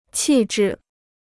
气质 (qì zhì) Free Chinese Dictionary